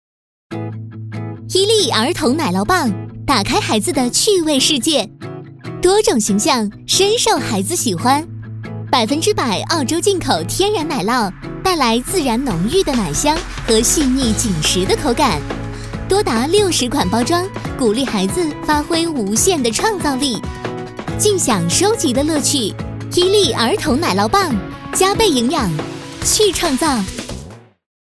女国132_广告_品牌形象_伊利儿童奶酪棒_可爱.mp3